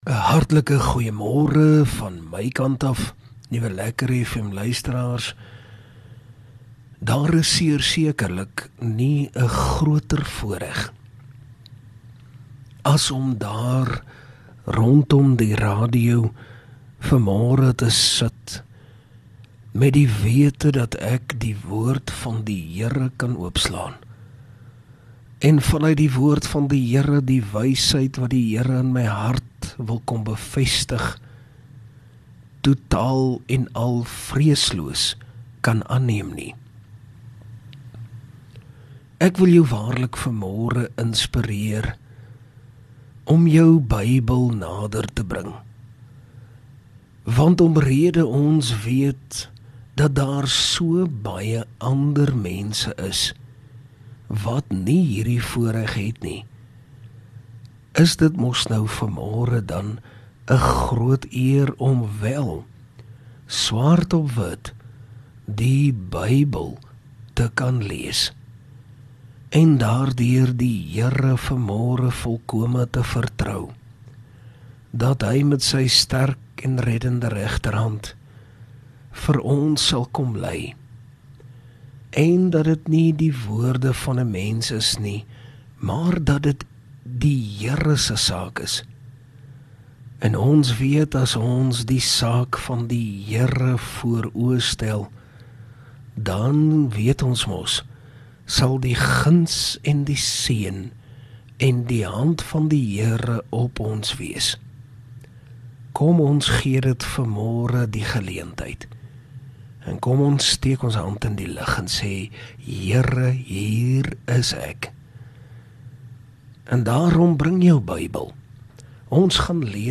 Oggendoordenking